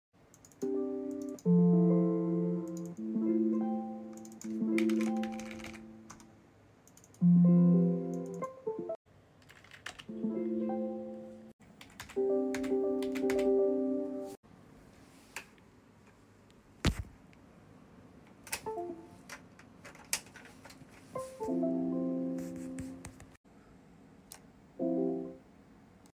sm better than modern Windows 10 sounds